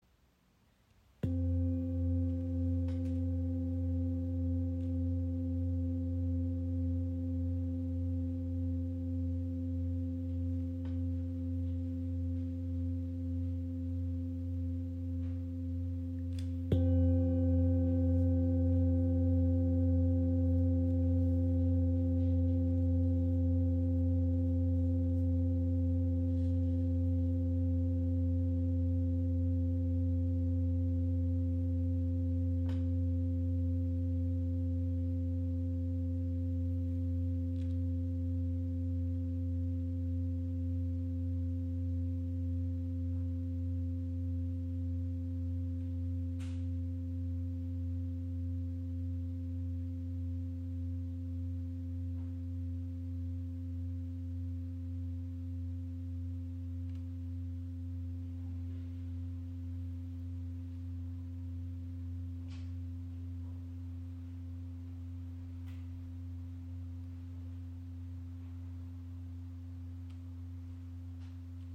Handgefertigte Klangschale aus Kathmandu
• Icon Inklusive passendem rotem Filzschlägel
• Icon Zentrierender, obertonreicher Klang im Ton ~ F 2 (~88 Hz)
Ihr obertonreicher Klang im Ton ~ F2 ist klar und zentrierend.
Klangschale | Katmandu | Blume des Lebens | ø 30 cm | Ton ~ F2 (~88 Hz)